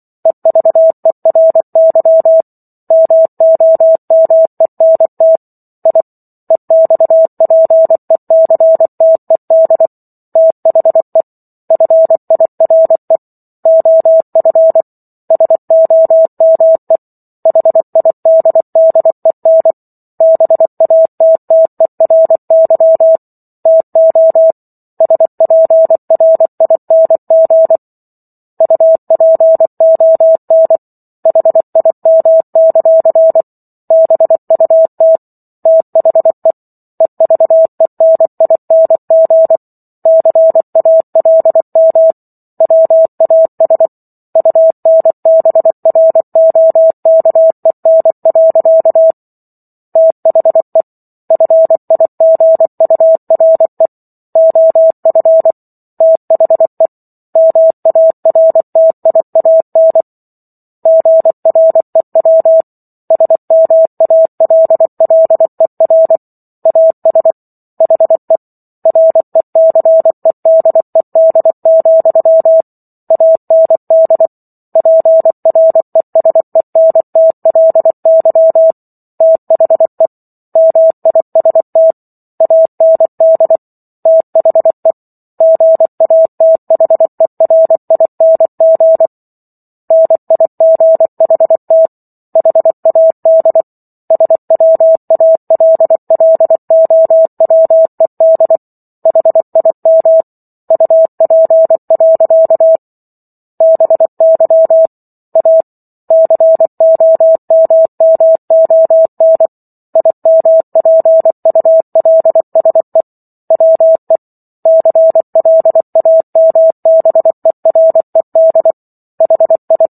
War of the Worlds - 15-Chapter 15 - 24 WPM